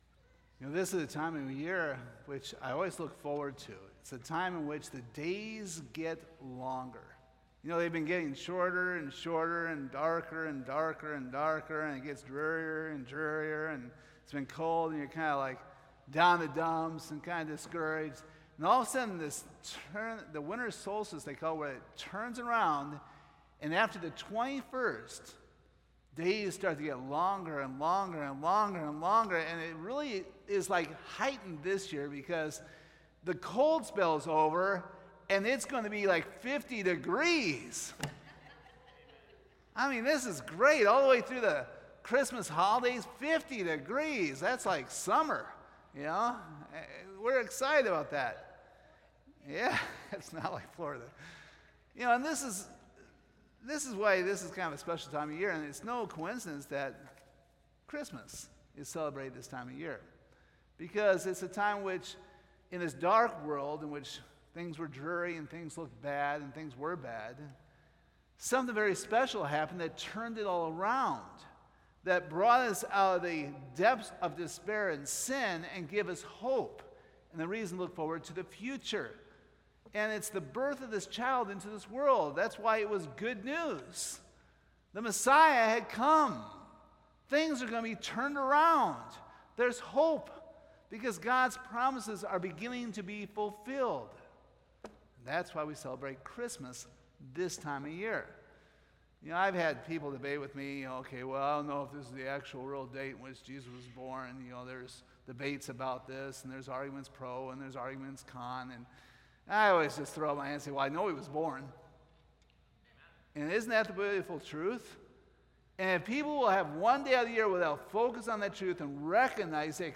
Isaiah 7:1-17 Service Type: Sunday Morning We hope you were blessed and challenged by the ministry of Calvary Baptist Church.